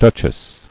[duch·ess]